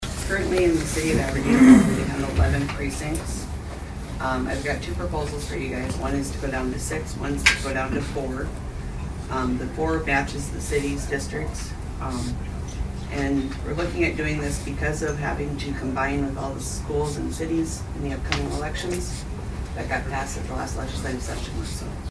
ABERDEEN, S.D.(HubCityRadio)- At Tuesday’s Brown County Commission meeting, the commissioners received a report from Brown County Auditor Lyn Heupel talking about the number of precincts within the city of Aberdeen.